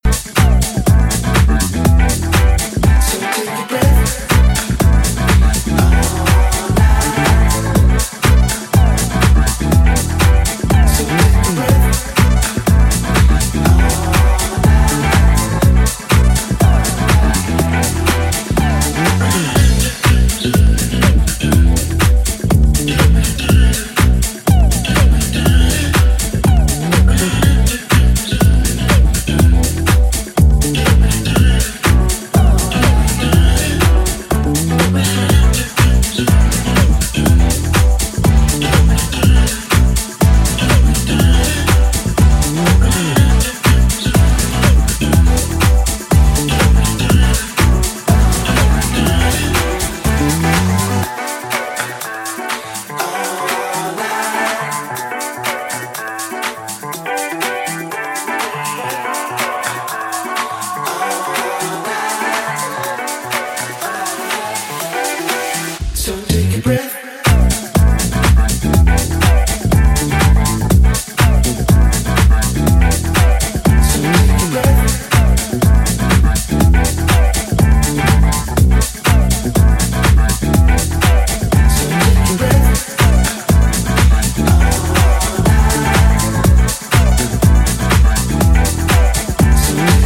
Pure dancefloor joy is guaranteed with this release.
very organic sounding, warm, soul-driven jazzy house songs